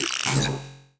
Hi Tech Alert 12.wav